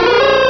Cri de Hoothoot dans Pokémon Rubis et Saphir.